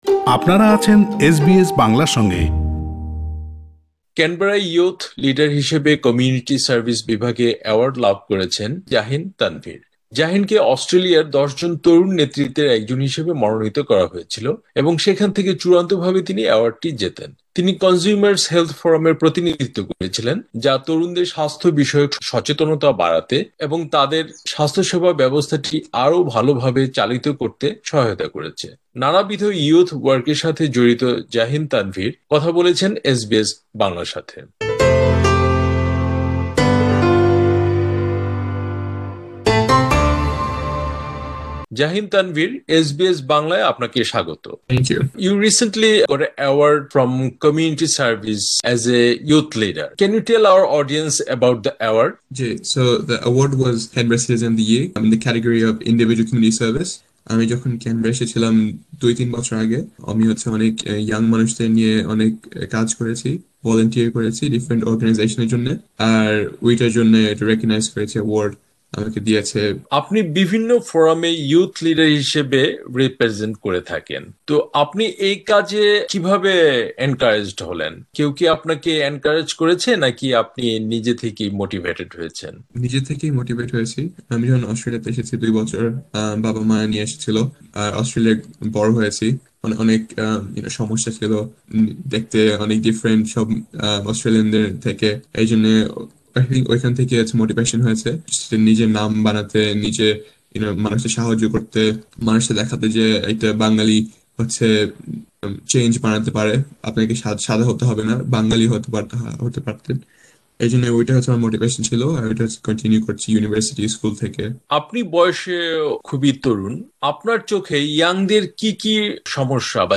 পুরো সাক্ষাৎকারটি